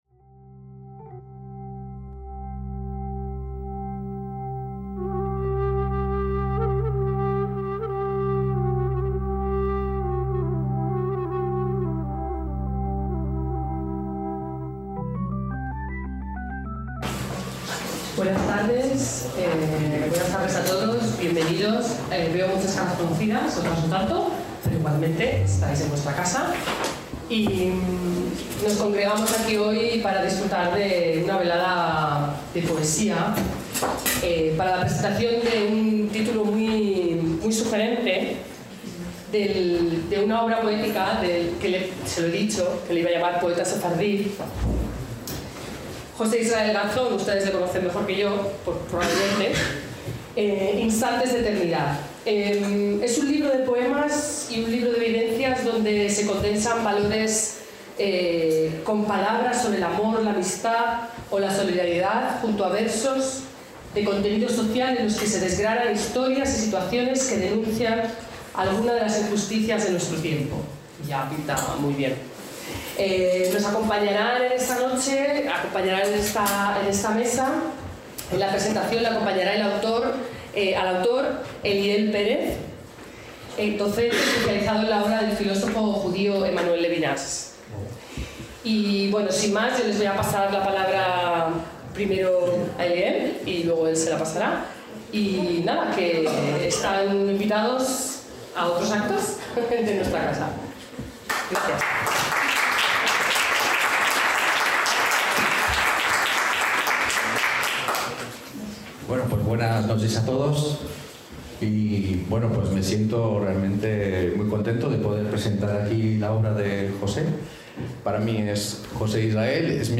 ACTOS EN DIRECTO - “Instantes de eternidad” es un libro de poemas y un libro de vivencias donde se condensan valores con palabras sobre el amor, la amistad o la solidaridad junto a versos de contenido social en los que se desgranan historias y situaciones que denuncian alguna de las injusticias de nuestro tiempo.